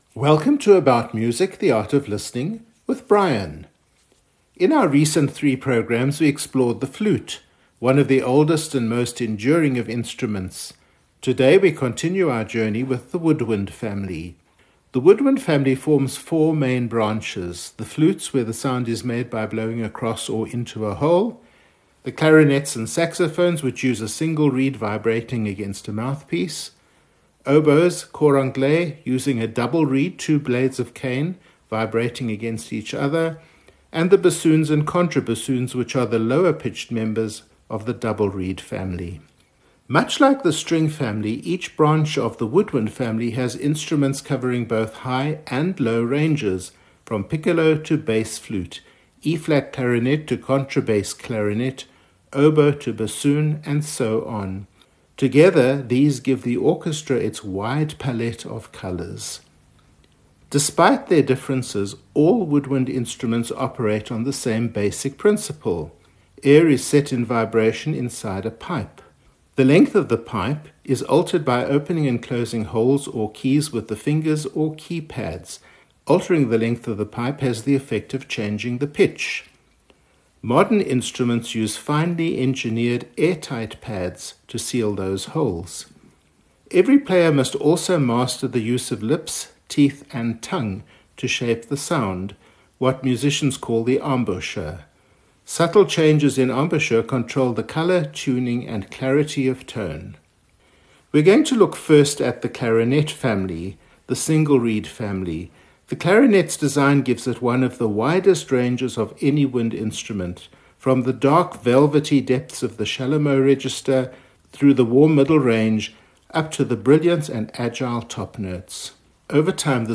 Wolfgang Amadeus Mozart – Clarinet Concerto in A Major, K. 622 Performed by Sabine Meyer with the Kammerorchester Basel conducted by Giovanni Antonini